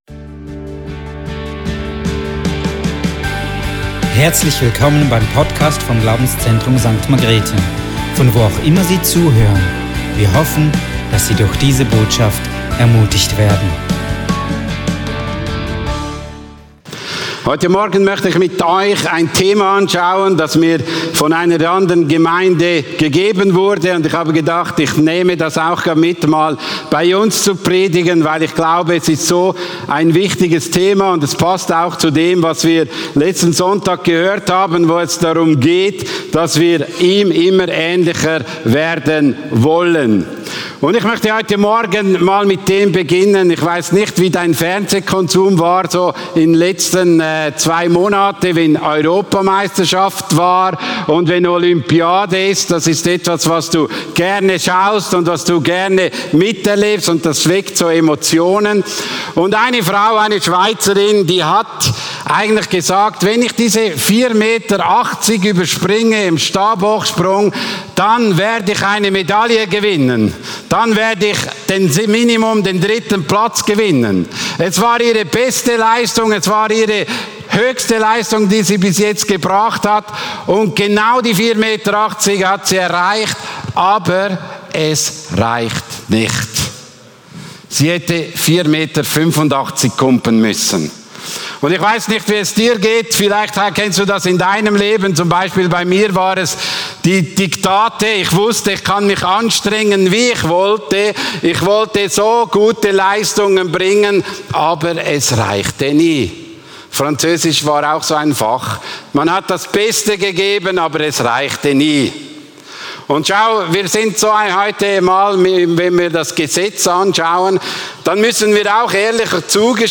Youtube-Predigt